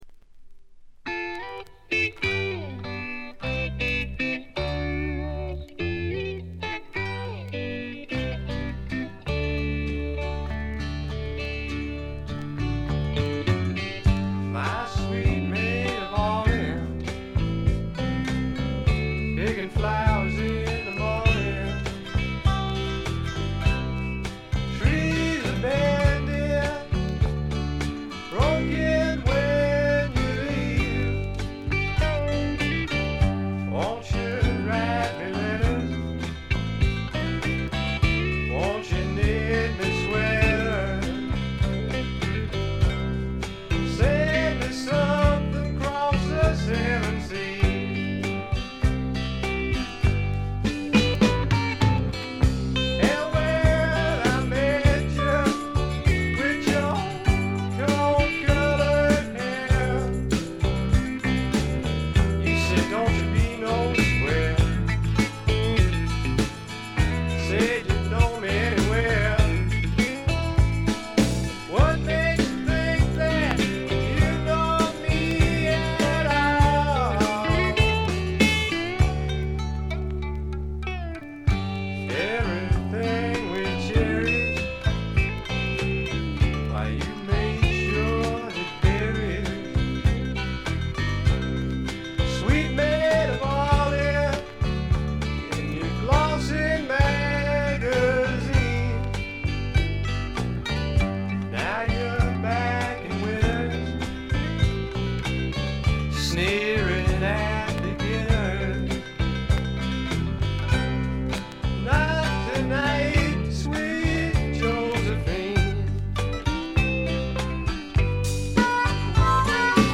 ところどころでチリプチ。
カントリー風味、オールド・ロックンロールを元にスワンプというには軽い、まさに小粋なパブロックを展開しています。
試聴曲は現品からの取り込み音源です。